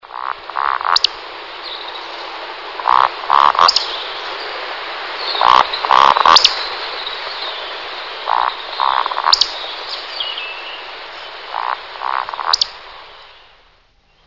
beccaccia c.wav